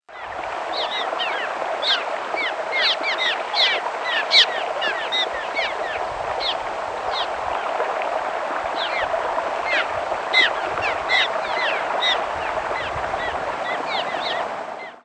Cliff Swallow Petrochelidon pyrrhonota
Flight call description A nasal, descending "nheew" and a low, harsh "jhrrr". Sometimes a higher, more pure-toned "heeo".
Diurnal calling sequences:
2. Minnesota June 10, 2001 (WRE). Birds in flight near breeding colony. Same site as Example 1.